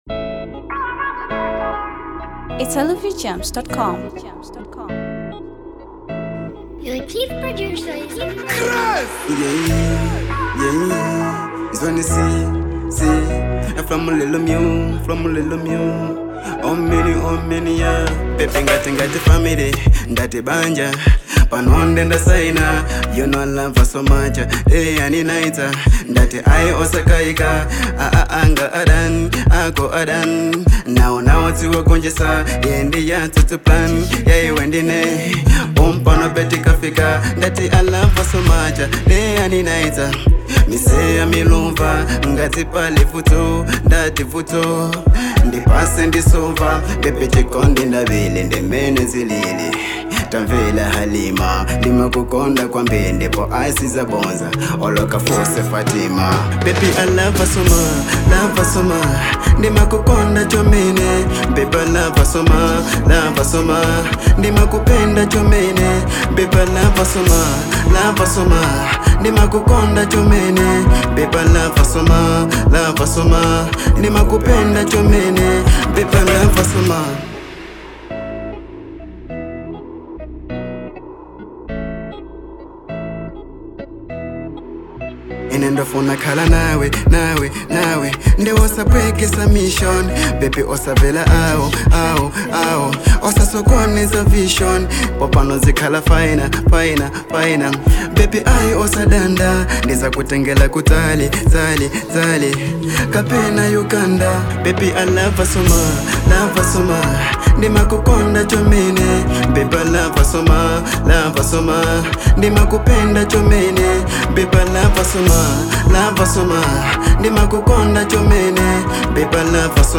Afro